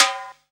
44_26_tom.wav